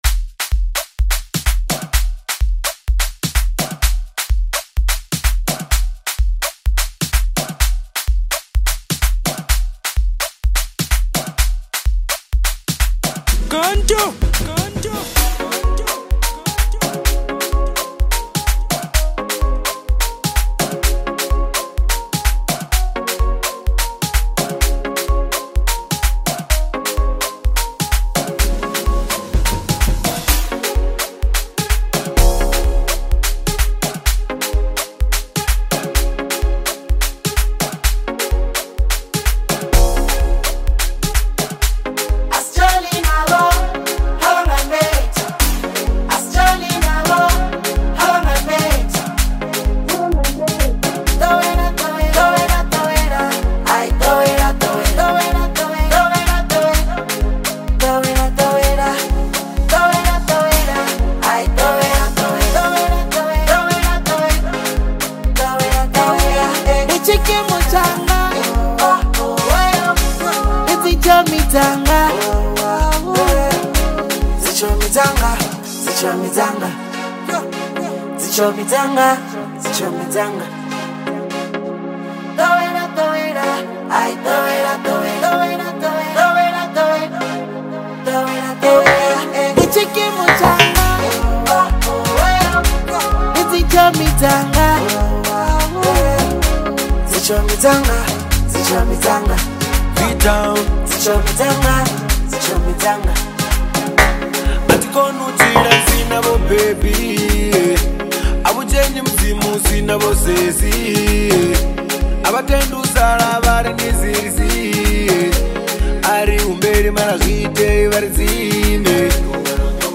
infectious and vibrant track